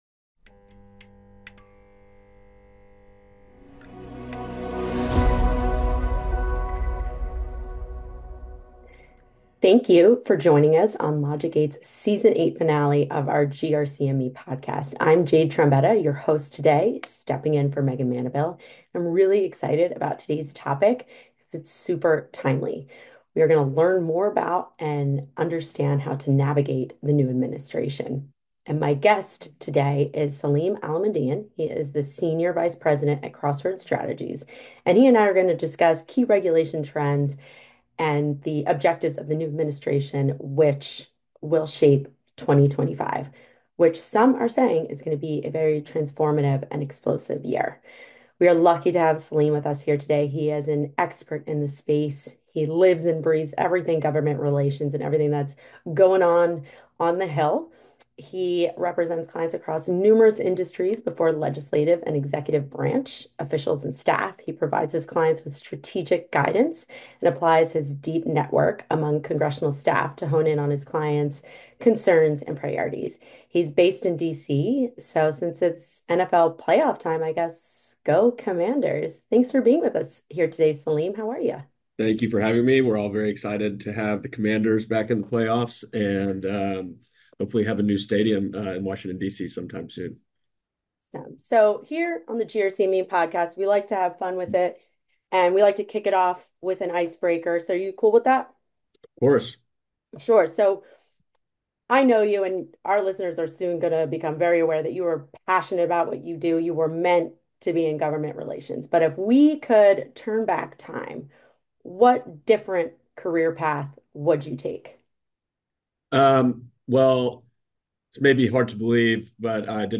Tune in for a conversation on the critical issues faced today.